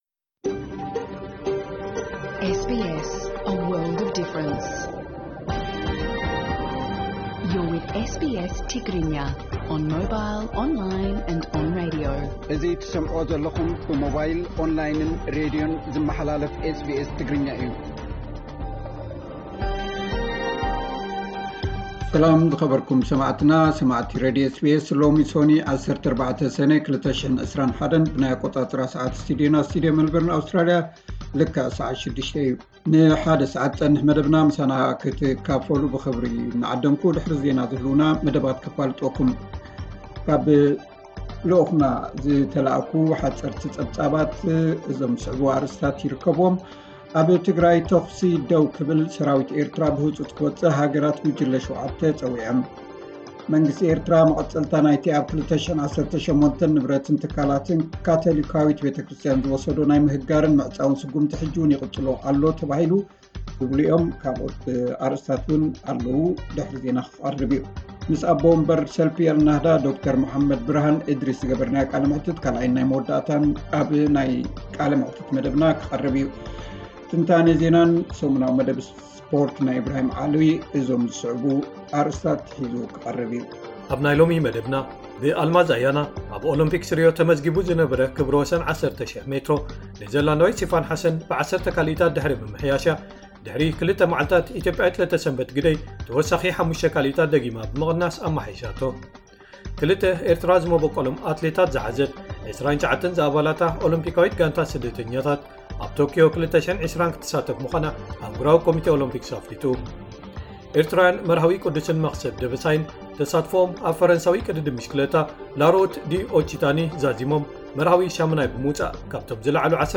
ዕለታዊ ዜና 14 ሰነ 2021 SBS ትግርኛ